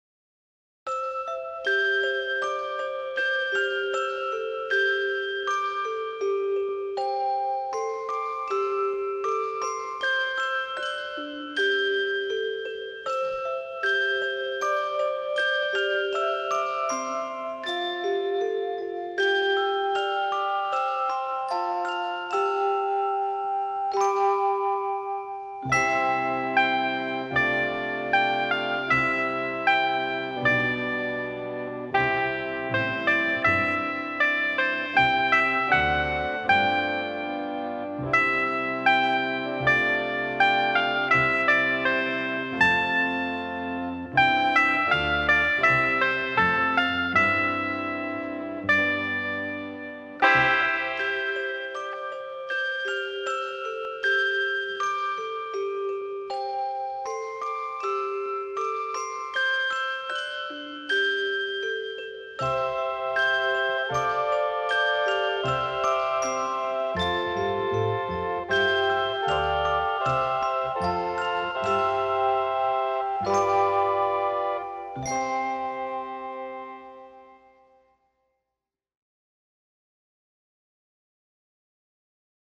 Tags: sounds horror halloween music ghosts